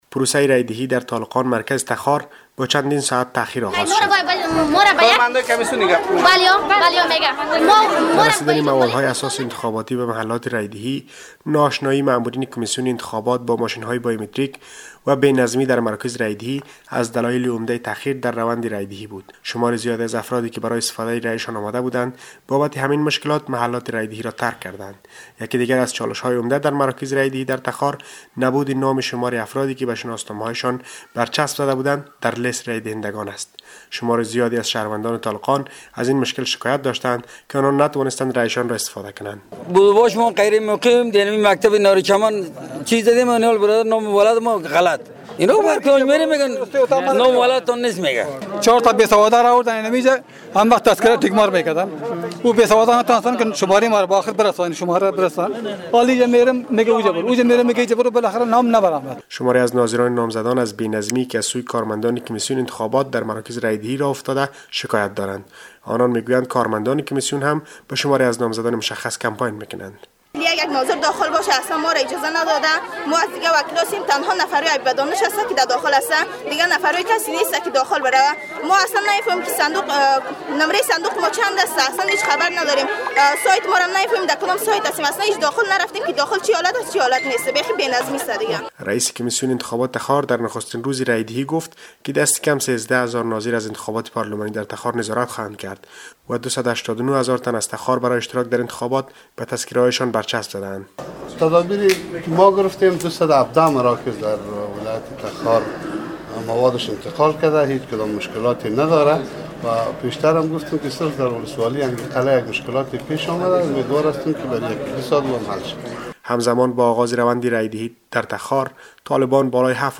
به گزارش خبرنگار رادیو دری ، یکی دیگر از چالش های عمده در مراکز رای دهی در تخار نبود نام شمار افرادی که به شناسنامه هایشان برچسب زده بودند در لیست رای دهندگان است.